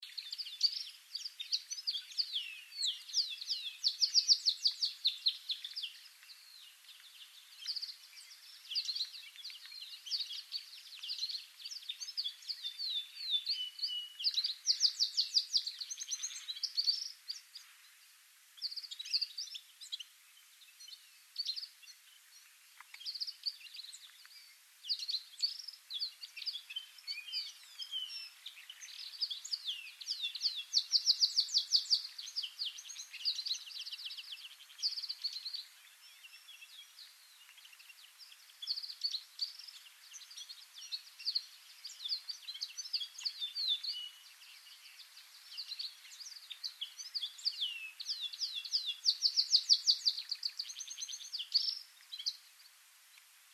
На этой странице вы можете слушать и скачивать звуки канарейки – удивительно мелодичные и чистые трели, которые украсят ваш день.
Звук веселого щебетания Канареек